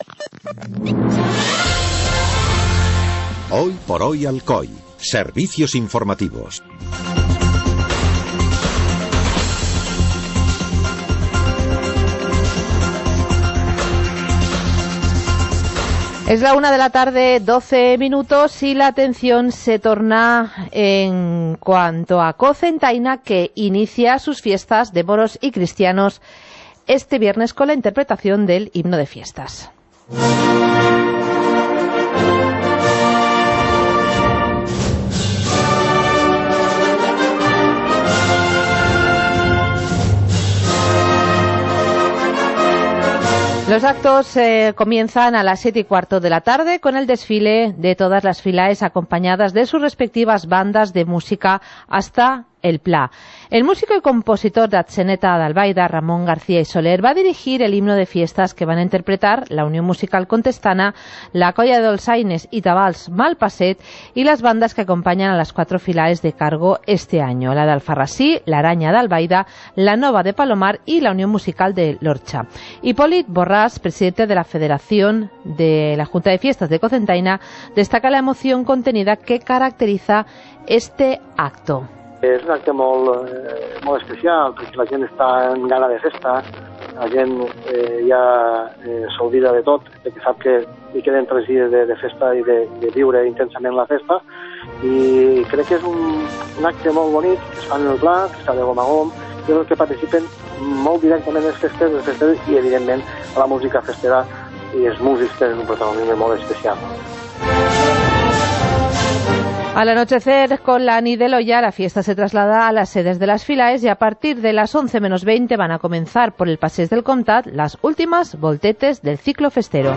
Informativo comarcal - viernes, 10 de agosto de 2018